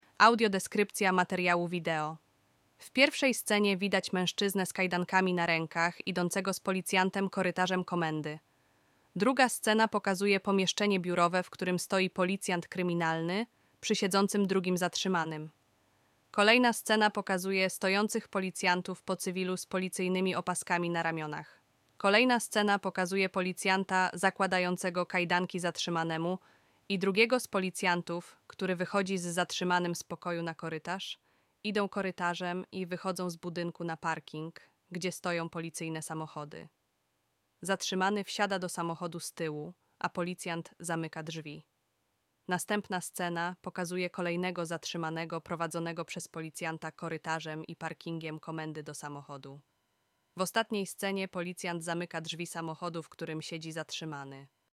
Nagranie audio audiodeskrypcja-wideo-rozboj-wrzesien-2024.mp3